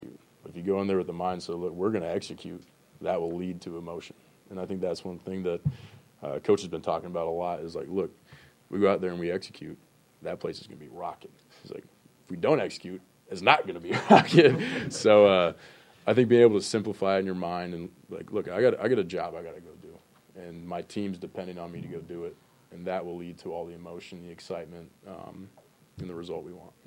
Florida quarterback Graham Mertz previewed a new season of Gator football and the opening matchup against No. 19 Miami in a news conference Monday.